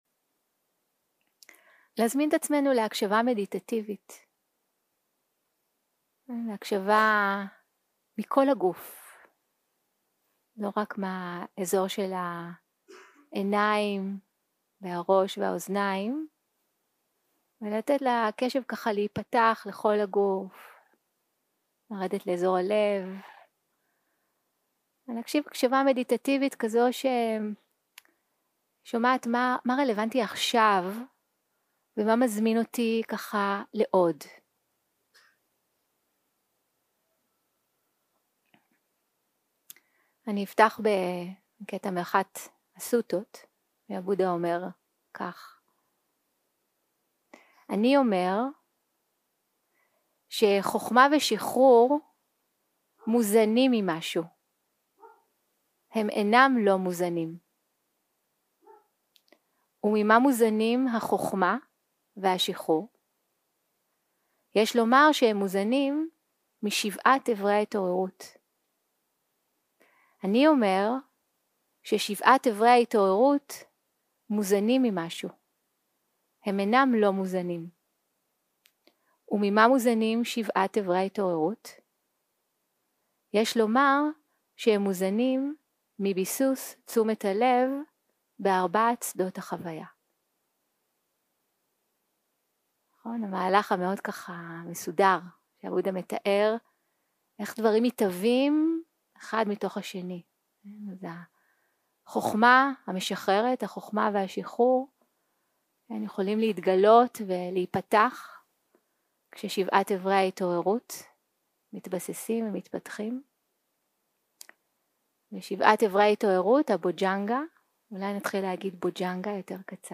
יום 2 - הקלטה 3 - צהרים - שיחת דהרמה - דהמה ויצ'יה - סקרנות רדיקלית 0:00 0:00 סוג ההקלטה: Dharma type: Dharma Talks שפת ההקלטה: Dharma talk language: Hebrew